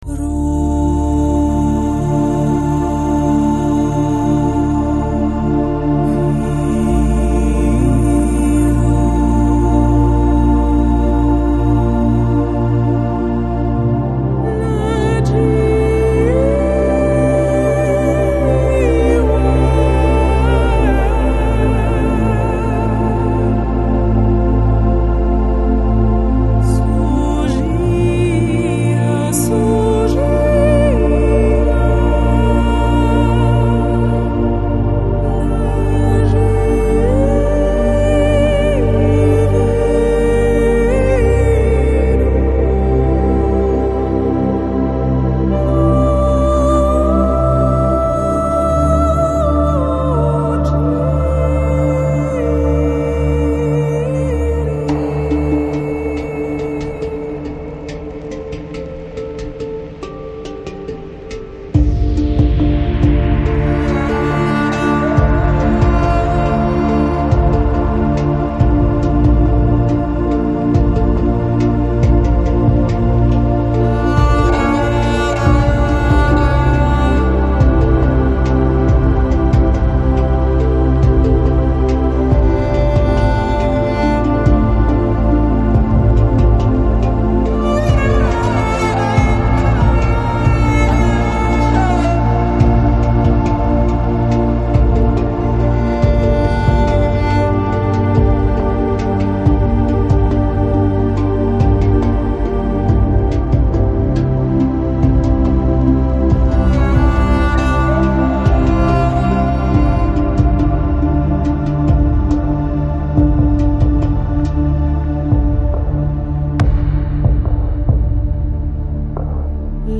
Жанр: Lounge, Downtempo, House, World, Electronic